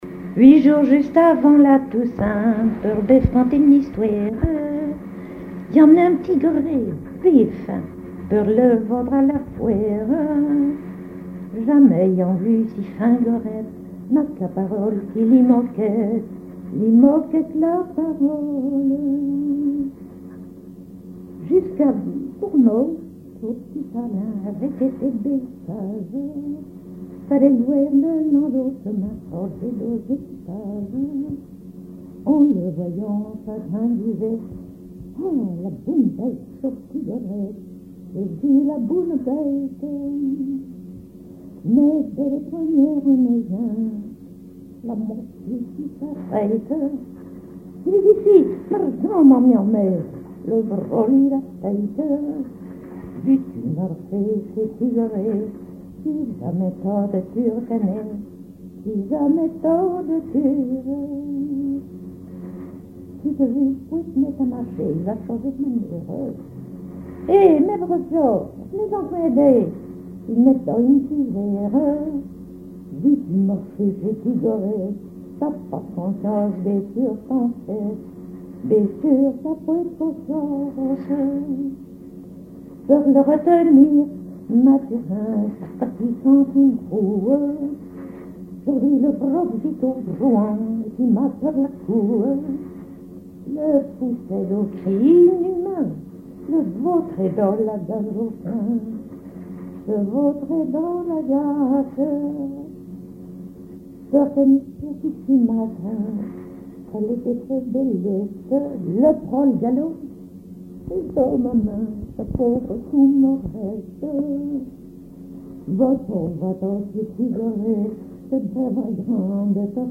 chansons, légende et témoignages
Pièce musicale inédite